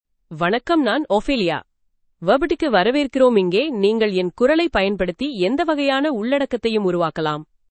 Ophelia — Female Tamil AI voice
Voice sample
Listen to Ophelia's female Tamil voice.
Female
Ophelia delivers clear pronunciation with authentic India Tamil intonation, making your content sound professionally produced.